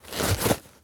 foley_sports_bag_movements_06.wav